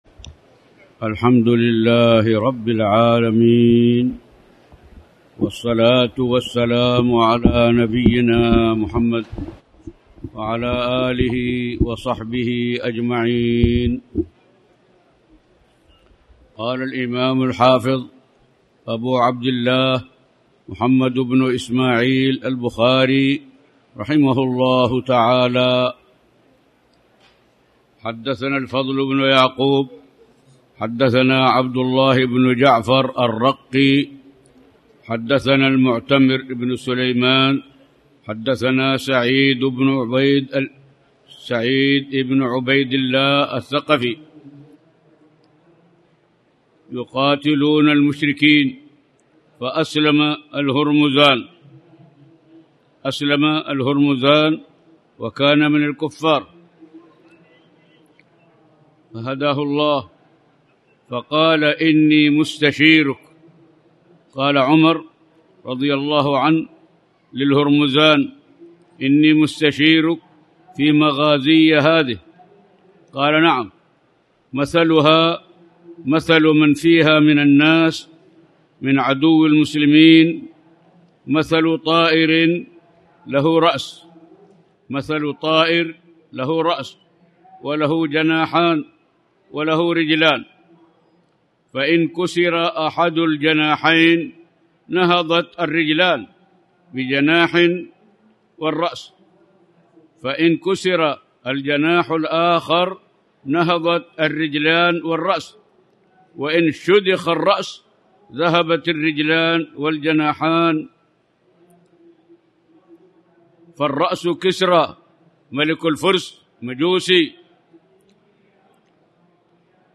تاريخ النشر ٢٨ محرم ١٤٣٩ هـ المكان: المسجد الحرام الشيخ